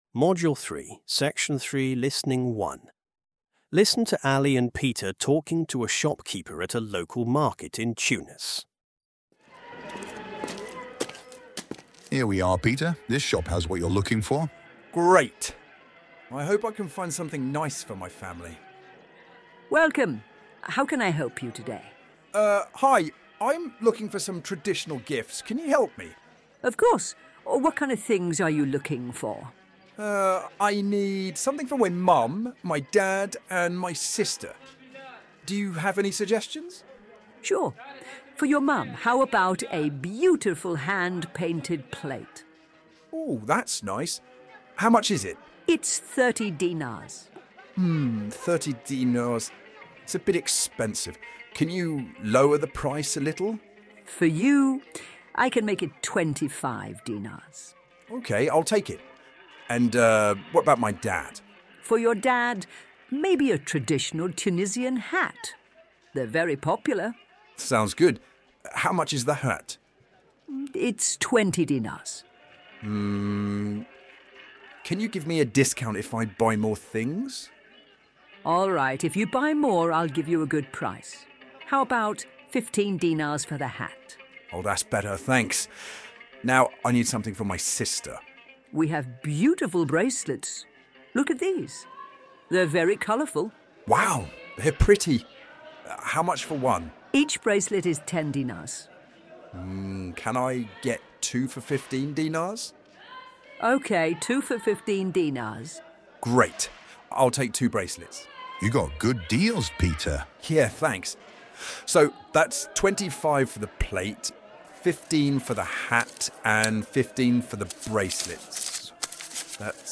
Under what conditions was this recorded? m1-s3-in-the-souk-market.mp3